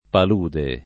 pal2de] s. f. — nella lingua ant., anche s. m.: Corsi al palude [